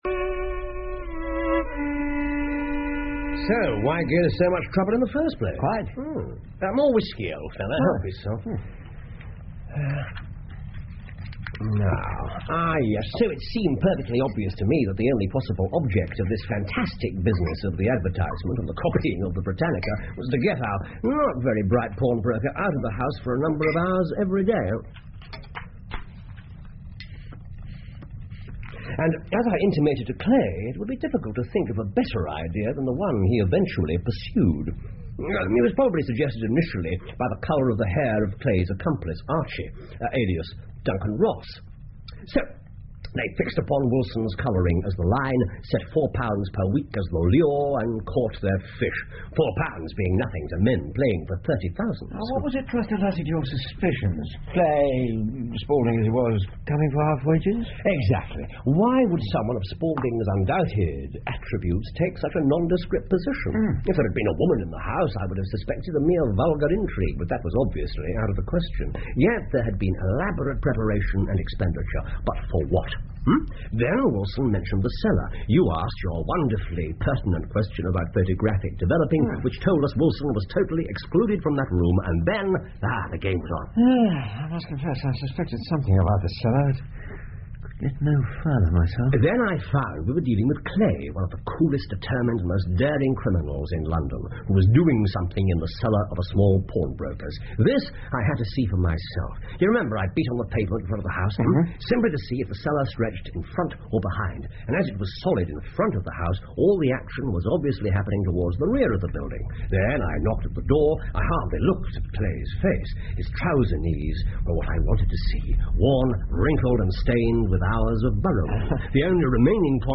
福尔摩斯广播剧 The Red Headed League 9 听力文件下载—在线英语听力室